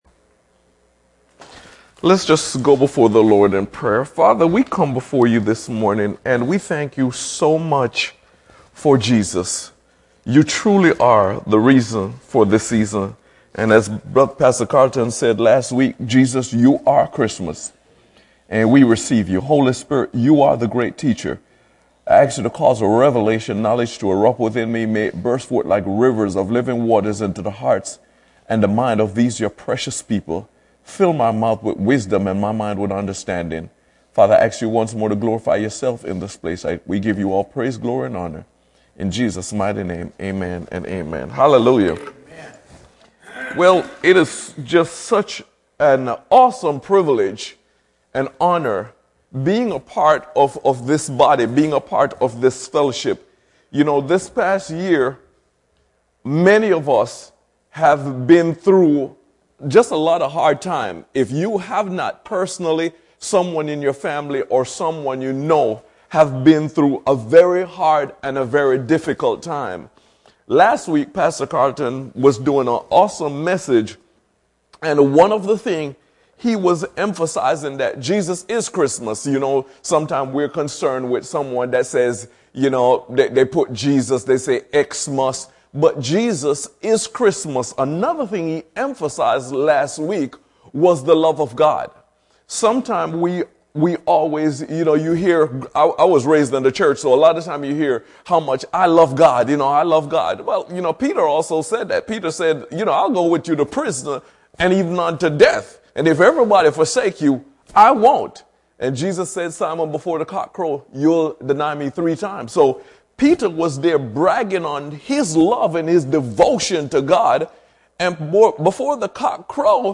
at Grace Cafe Church The peace of God is not measured by external circumstances, it is residing deeply in the innermost parts of your being.